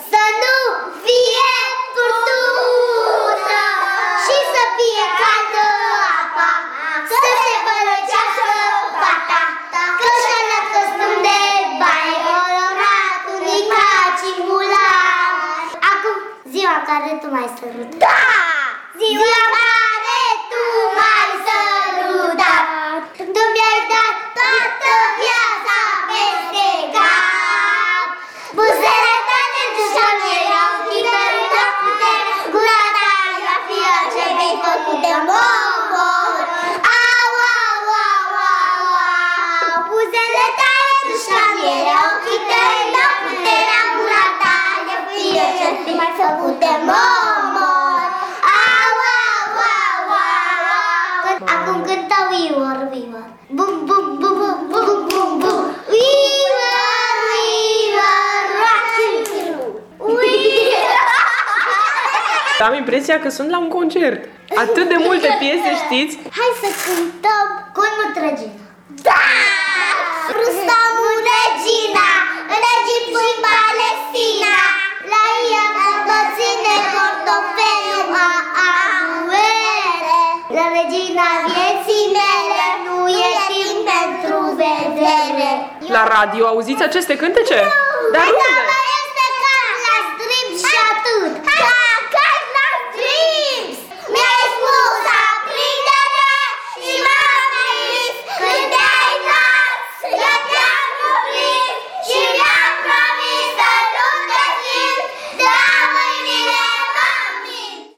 Preșcolarii de la grădinița Arlechino din Târgu Mureș pot fi cu adevărat surprinzători când vine vorba de talentele lor muzicale. Pentru a-i ajuta să-și arate abilitățile vocale le-am propus să se gândească la cântecele cunoscute și să se bucure de experiența unui concert la grădiniță.
26-aprilie-Copilarii-Concert-la-gradinita.mp3